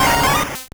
Cri de Boustiflor dans Pokémon Or et Argent.